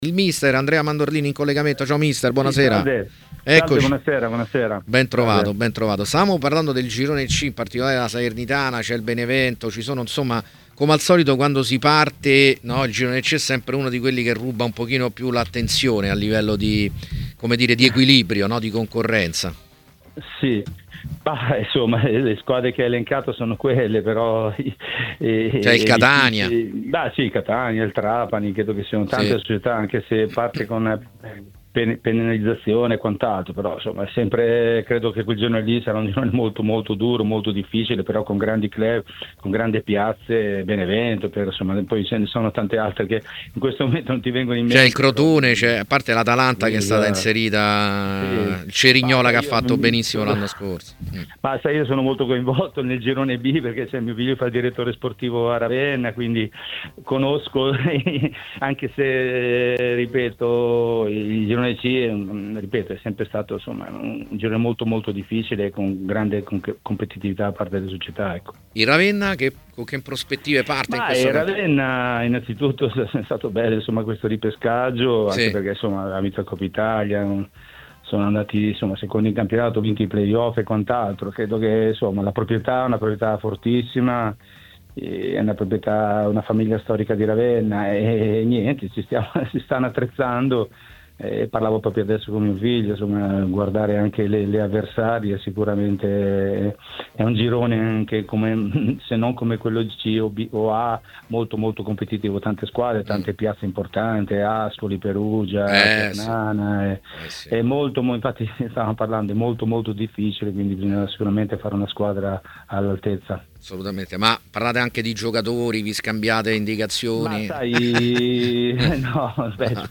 L'esperto allenatore Andrea Mandorlini è intervenuto nel corso della trasmissione " A Tutta C " su TMW Radio per commentare vari temi relativi al campionato di Serie C: